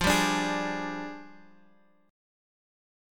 F 6th Suspended 2nd Flat 3rd